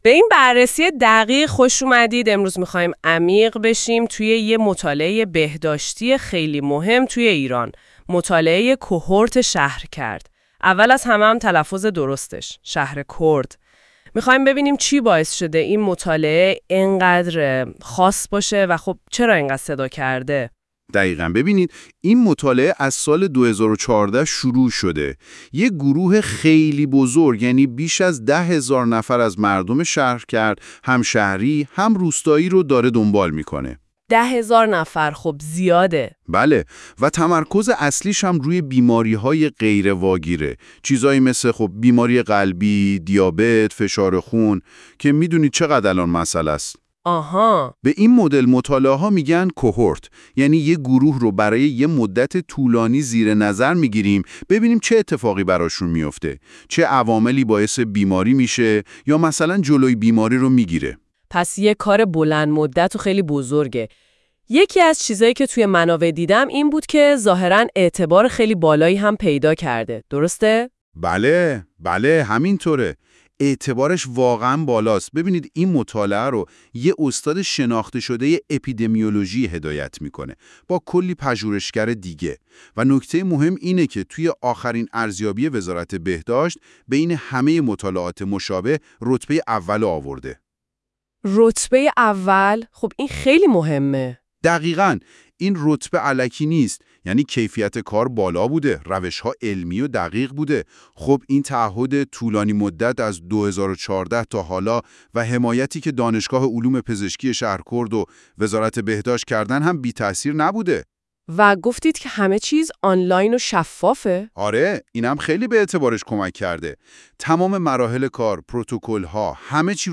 دانلود پادکست خلاصه ارزشیابی درونی با AI